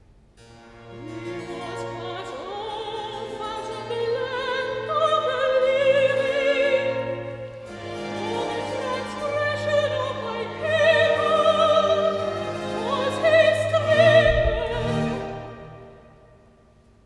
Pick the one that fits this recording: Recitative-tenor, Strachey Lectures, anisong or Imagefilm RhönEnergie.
Recitative-tenor